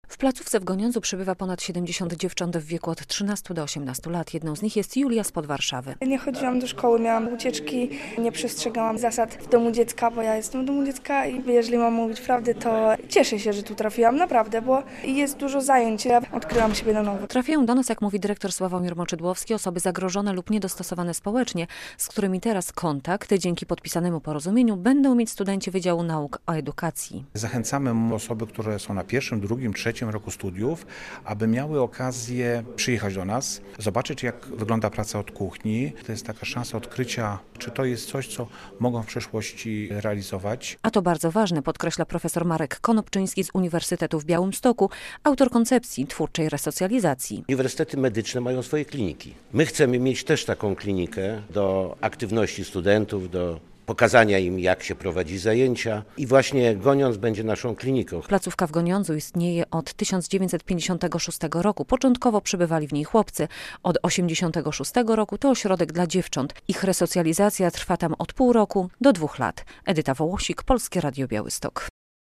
Radio Białystok | Wiadomości | Wiadomości - UwB będzie współpracować z ośrodkiem wychowawczym z Goniądza